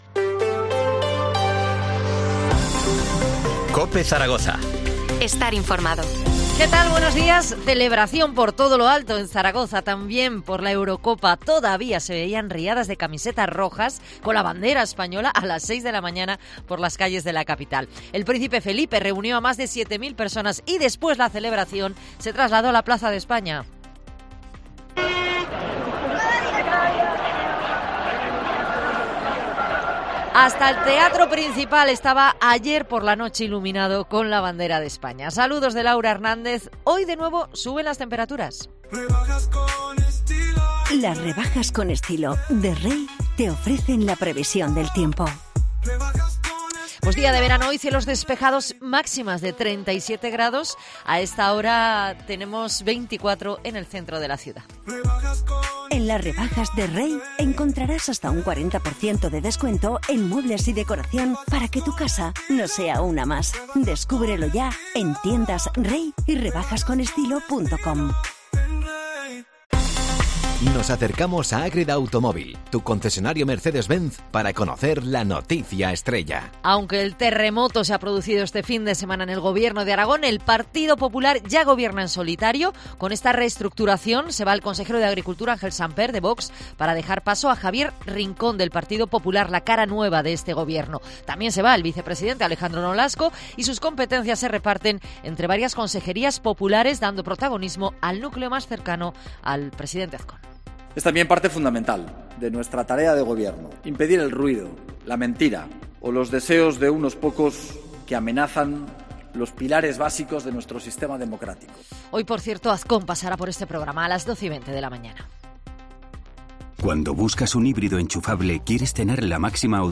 AUDIO: Titulares del día en COPE Zaragoza